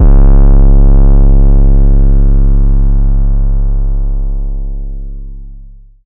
808 [ Phat ].wav